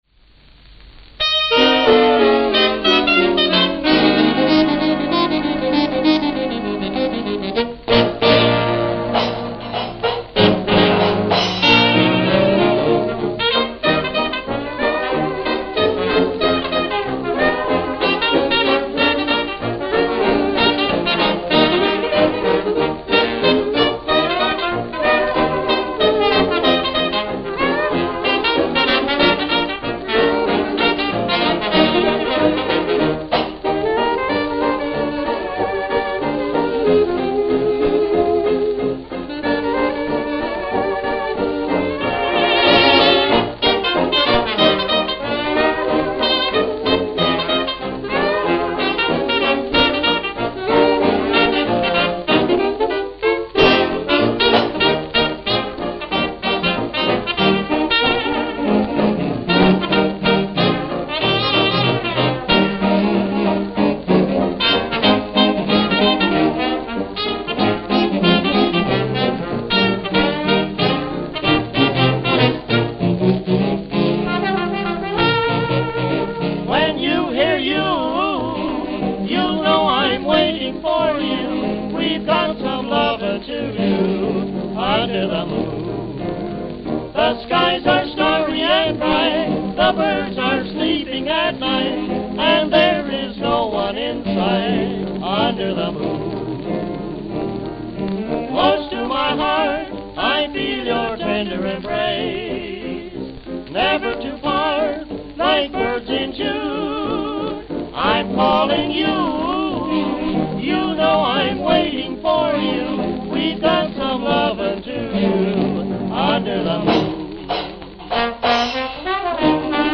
Chicago, Illinois Chicago, Illinois
Note: Worn.